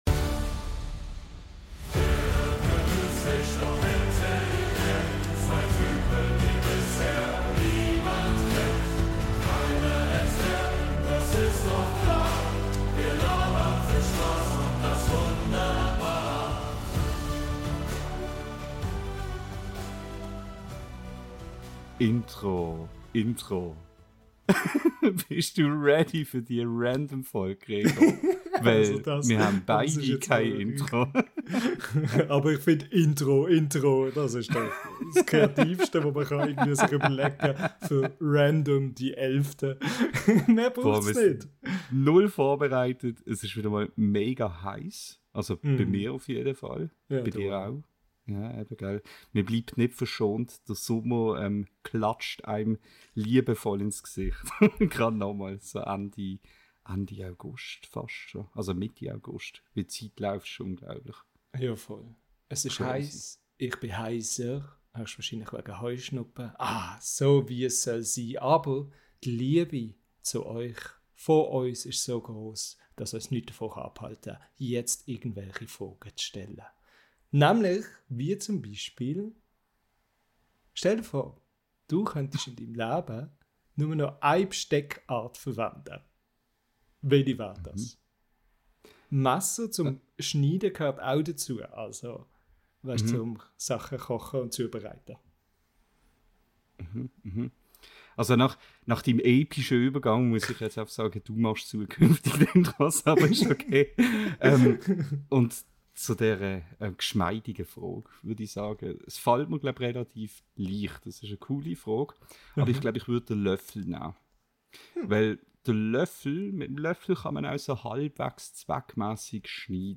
Beschreibung vor 7 Monaten Unsere neue Randomfolge im schweizerdeutschen Podcast ist genau das Richtige, wenn ihr zum Beispiel geniale Geschäftsideen für die nächste Streetparade sucht, wissen wollt, warum sich unsere Wut gegen den mysteriösen PET-Mann richtet oder wie viele Gewürze man im Leben wirklich braucht.